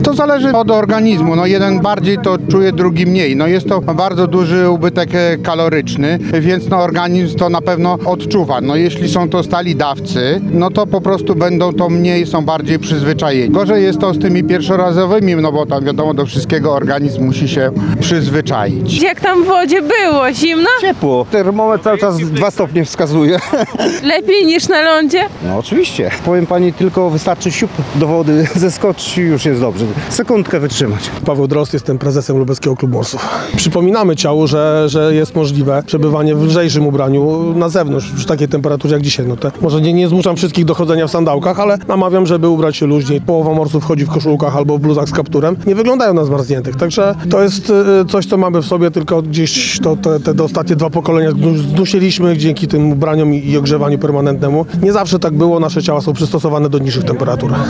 POSŁUCHAJ RELACJI Z TEGO WYDARZENIA: